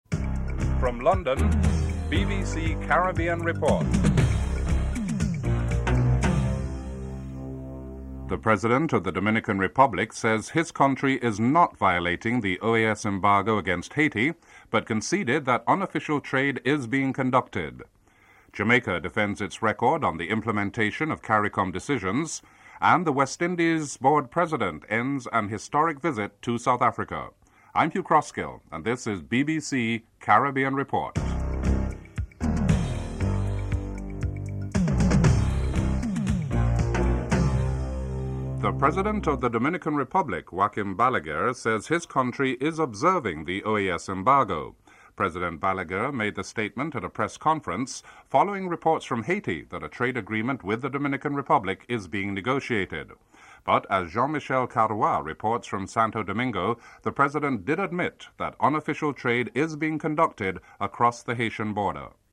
3. A correspondent in Santa Domingo reports on the unofficial trade being conducted which is admitted to by the President (01:02-02:26)
11. Interview with Clyde Walcott and his observationsin South Africa; particularly on improvements on race relations (11:22-14:26)